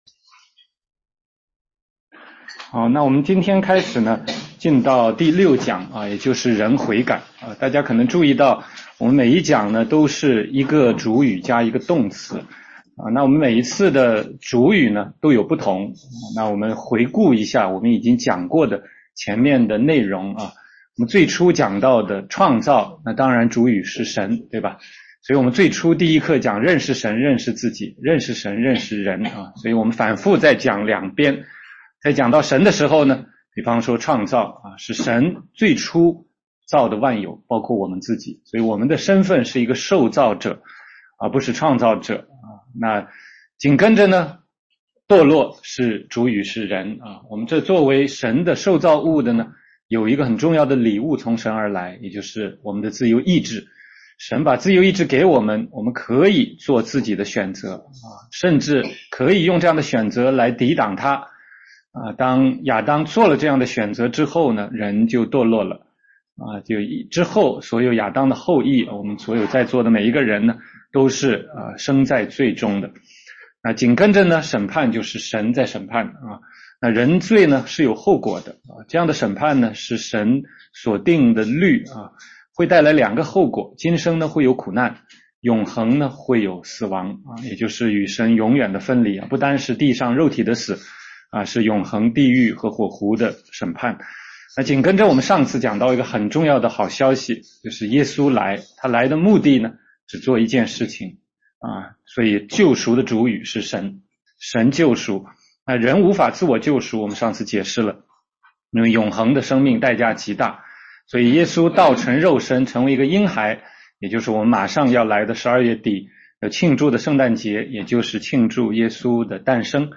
16街讲道录音 - 福音基础
得救的福音第六讲.mp3